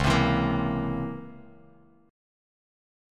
C#mM13 Chord
Listen to C#mM13 strummed